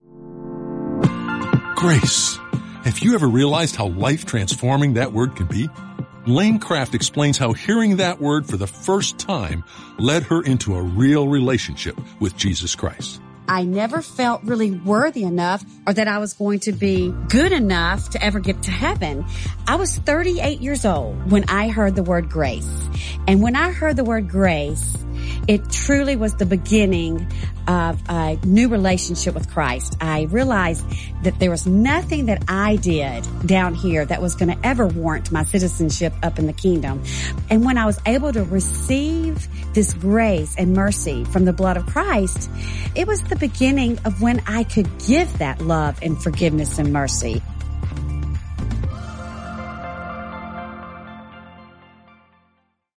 Each day, you’ll hear a short audio message with simple ideas to help you grow in your faith.